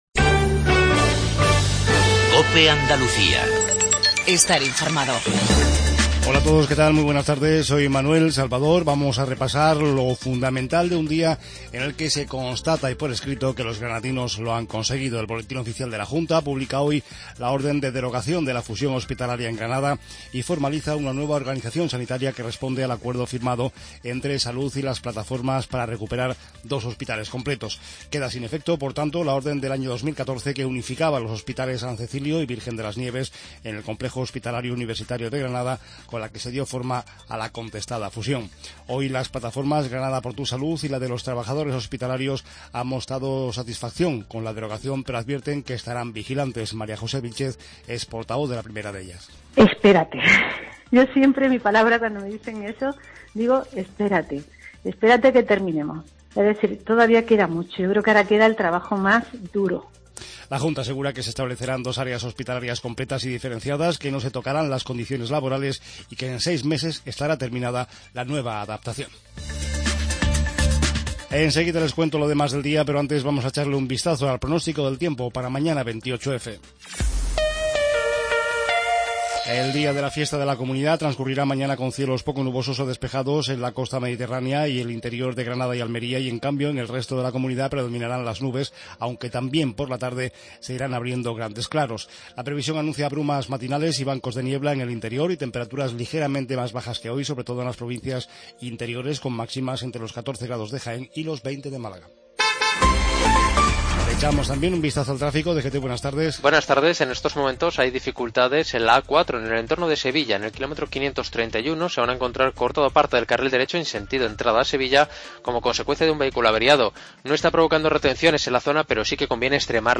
INFORMATIVO REGIONAL TARDE